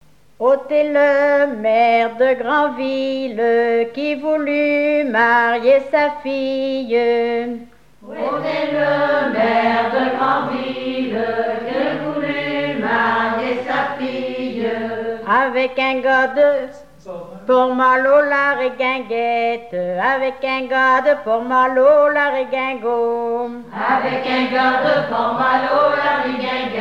chansons dont de marins
Pièce musicale inédite